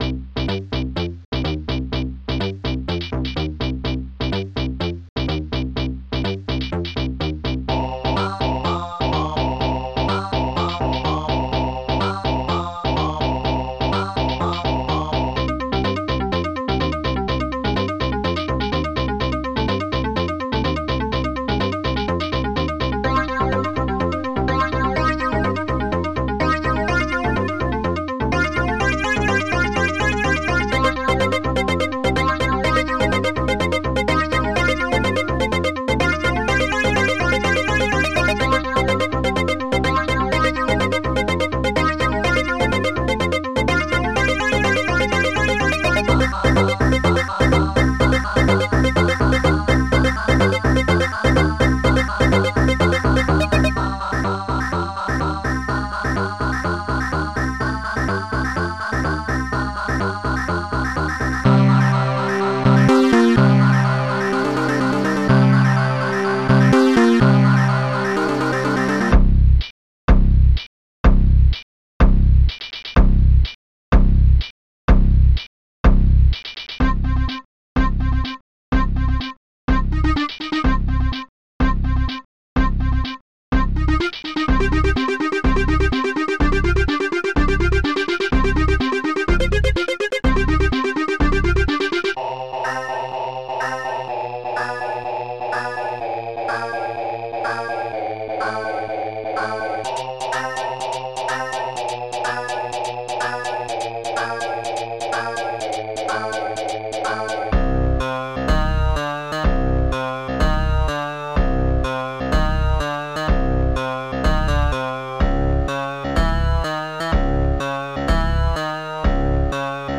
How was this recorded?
Fast Tracker M.K.